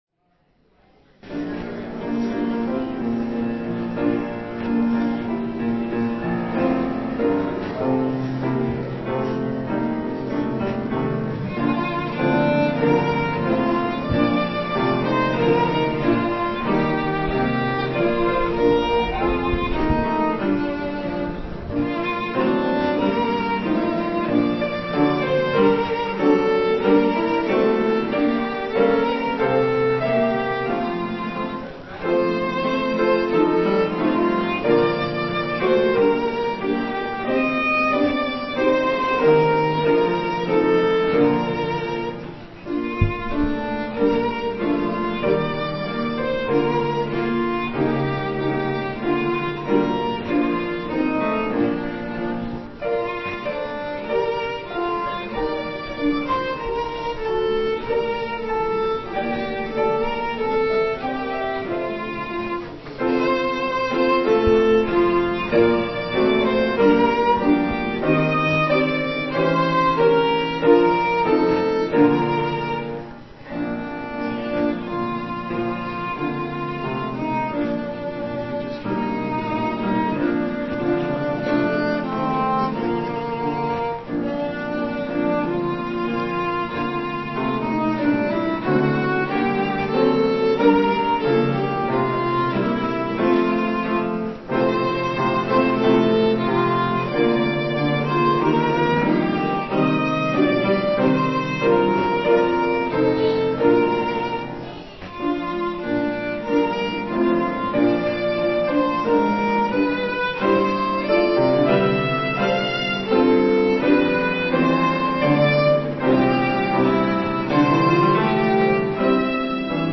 "All Is Well" Christmas Musical
piano
violin
trumpet
Violin duet
piano accompaniment